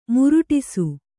♪ muruṭisu